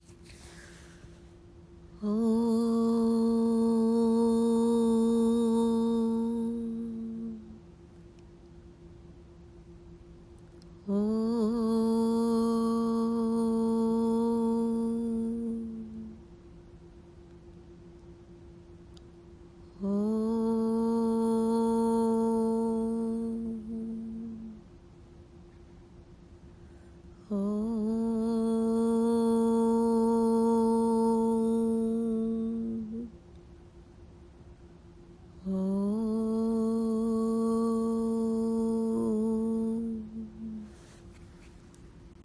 Om-AUM-Mantra-Download.m4a